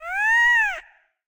ghastling7.ogg